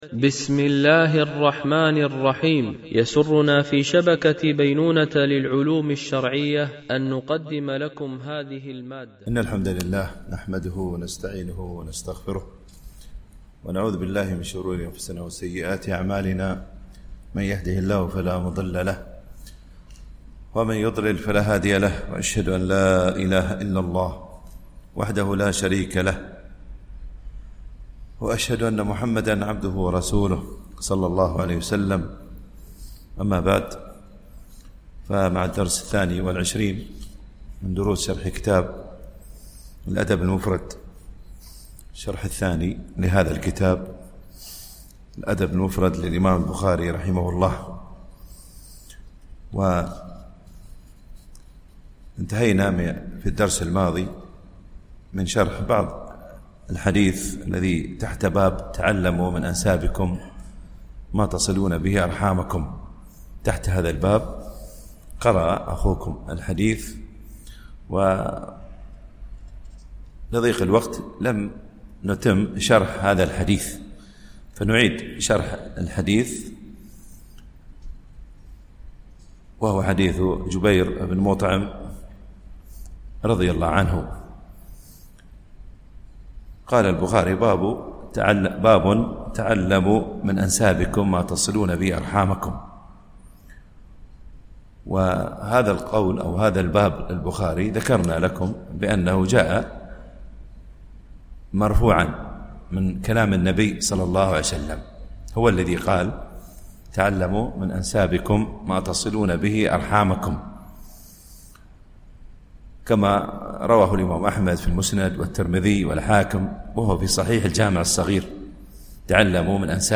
الشرح الثاني للأدب المفرد للبخاري - الدرس 22 ( الحديث 73 - 76 )